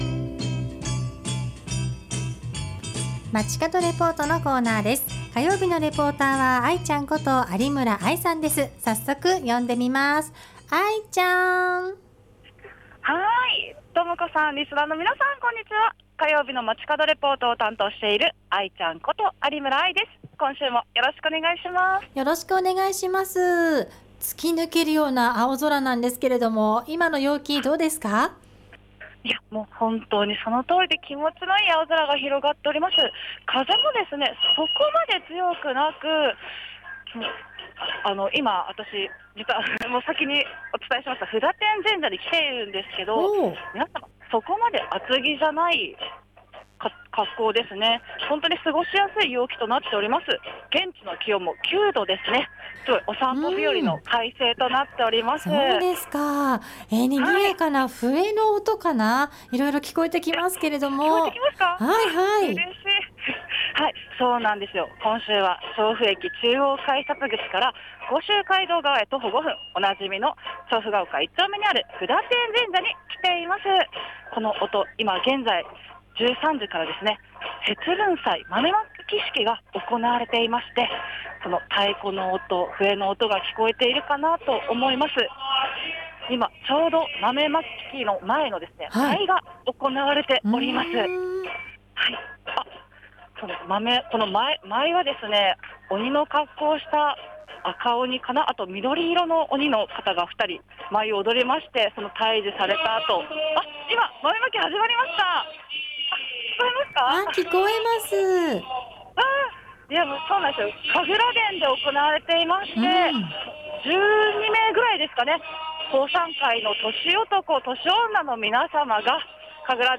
街角レポート